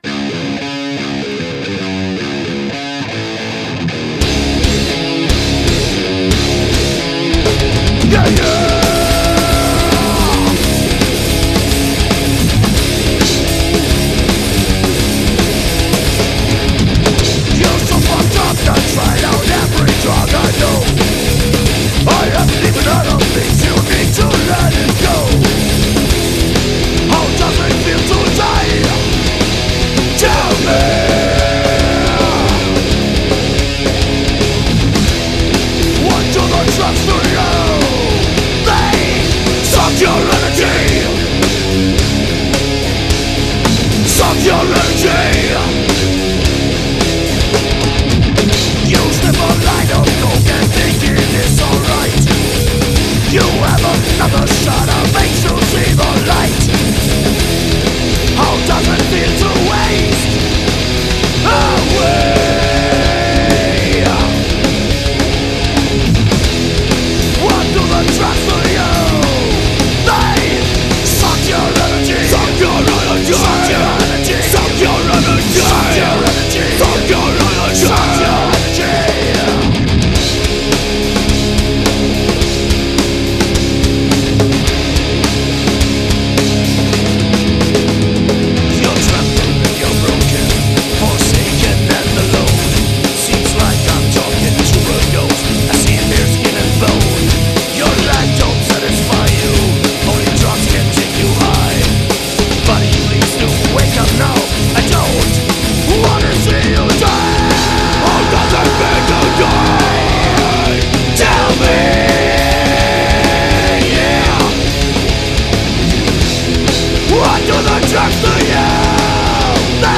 16-Spur/Studio